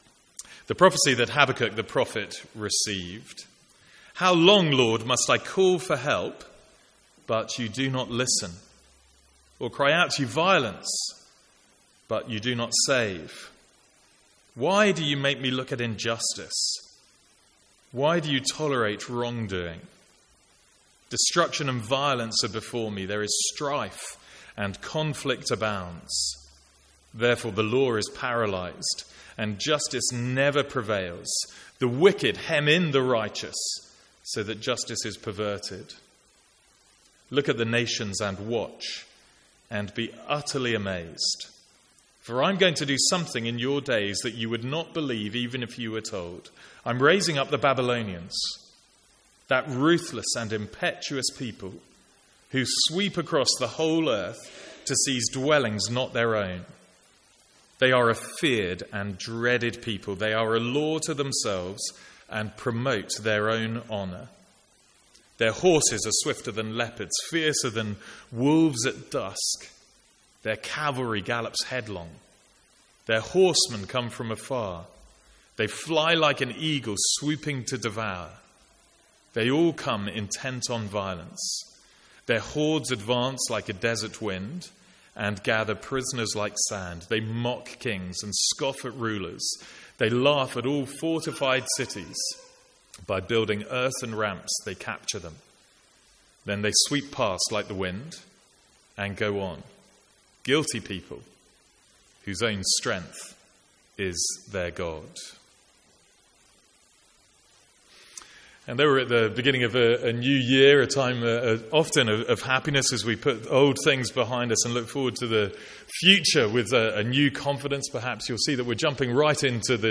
Sermons | St Andrews Free Church
From the Sunday morning series in Habakkuk.